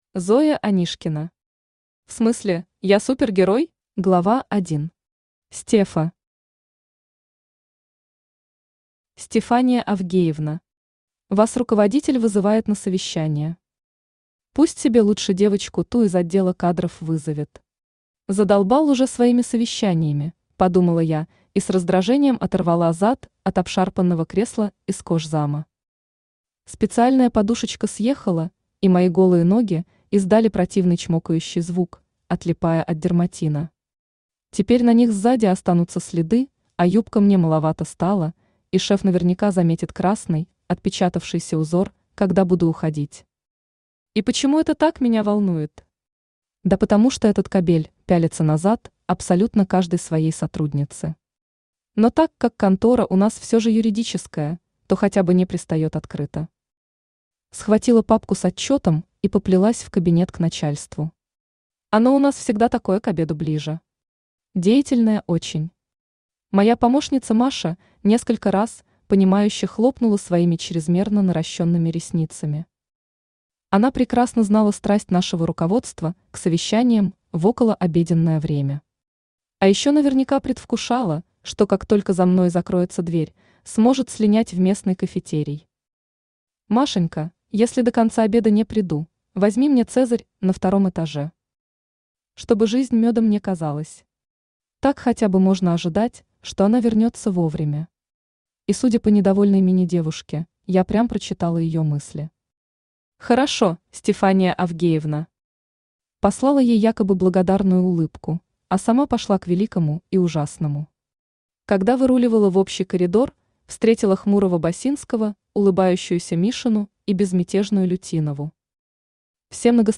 Аудиокнига В смысле, я супергерой?
Автор Зоя Анишкина Читает аудиокнигу Авточтец ЛитРес.